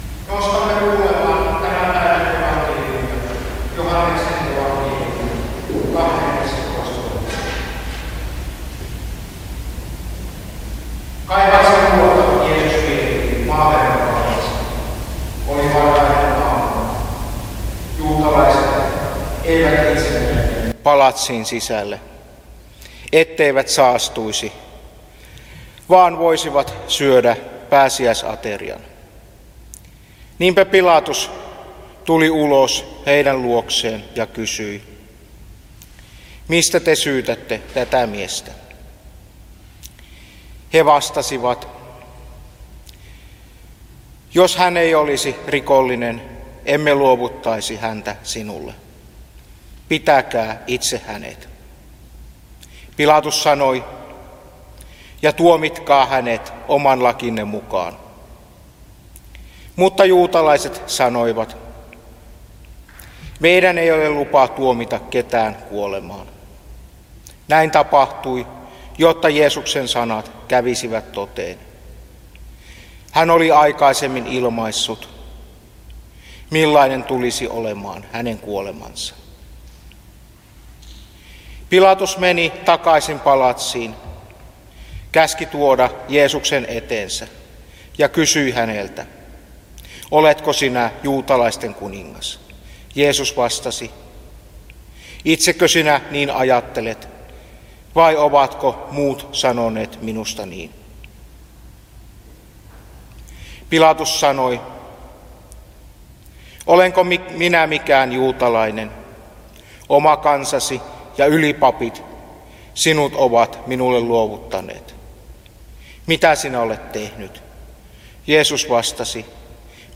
ahtisaarna Teuvassa hiljaisen viikon tiistaina Tekstinä Joh. 18: 28-40